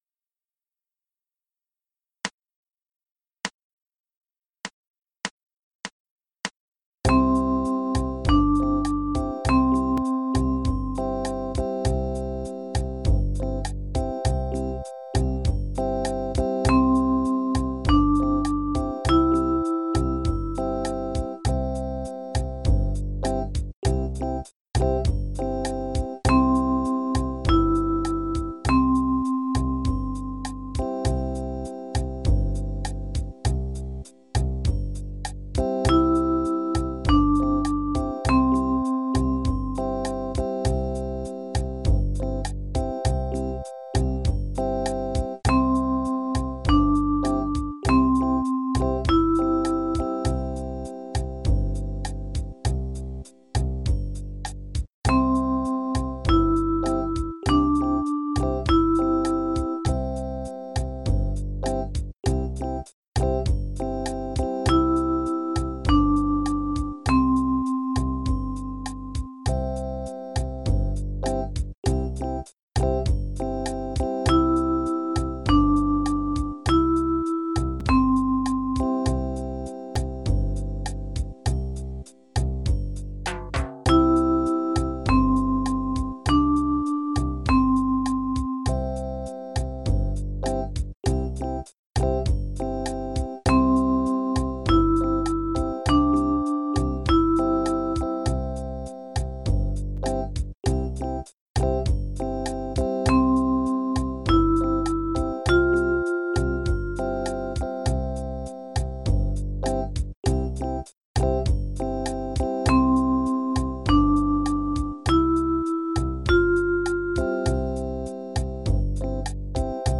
The notes used in these exercises are the first five notes of the major scale. For the key of C major, these notes are C, D, E, F and G. In each of the exercises, a two-measure pattern of notes is played. Listen to the pattern, then play it in the next two measures.